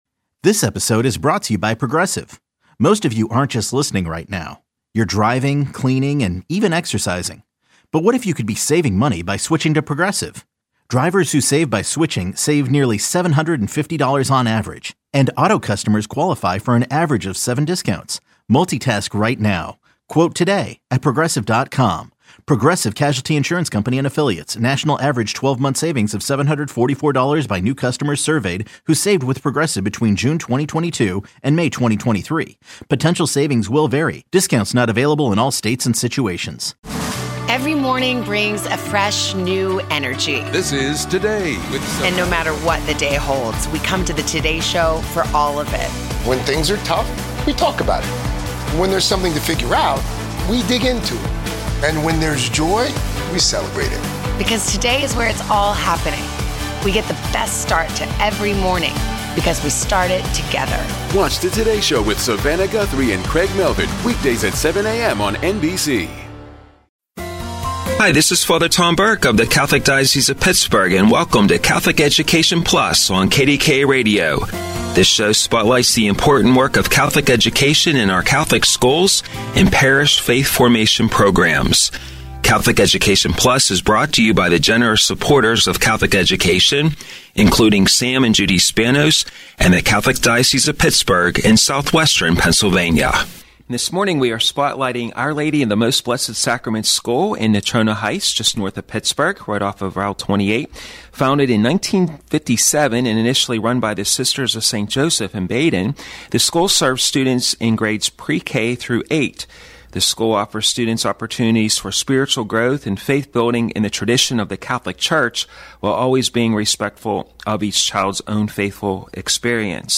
Helping children get to heaven- an interview